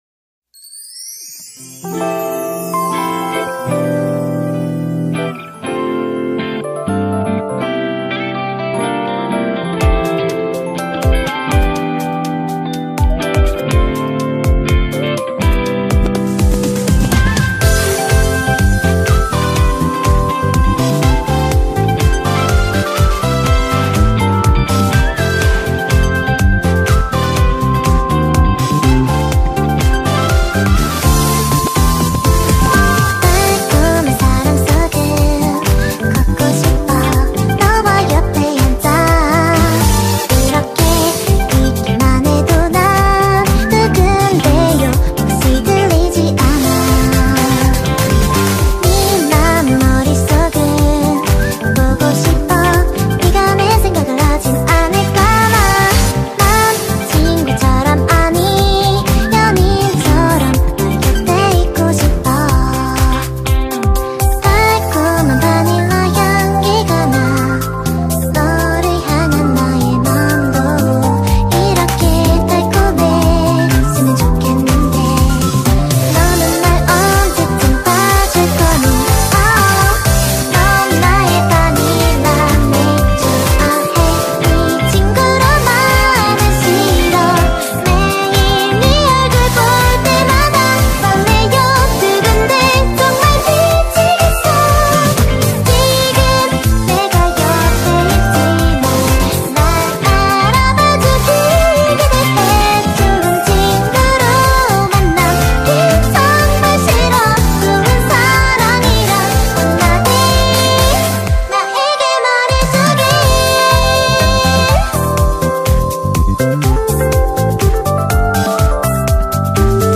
BPM61-123
Audio QualityCut From Video